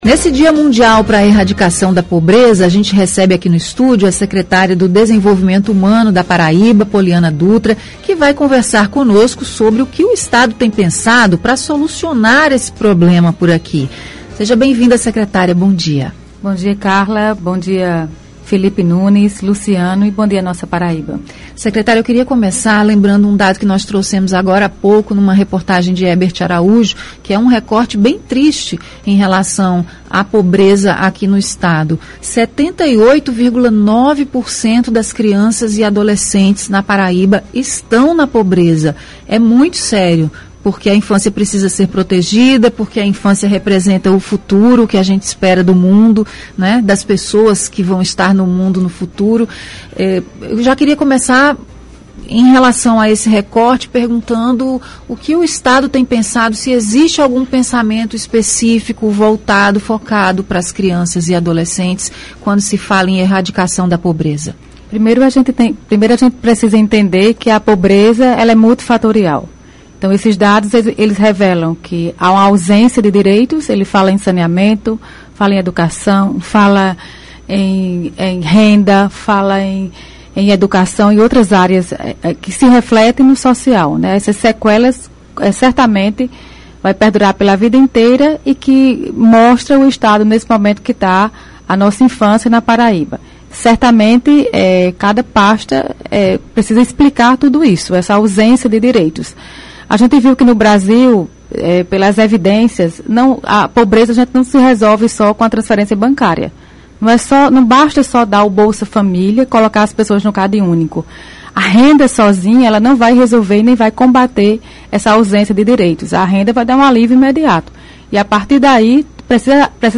Entrevista: secretária do Desenvolvimento Humano, Pollyanna Dutra fala sobre pobreza na PB – CBN Paraíba
A secretária do Desenvolvimento Humano da Paraíba, Pollyanna Dutra, foi entrevistada nesta terça-feira (17).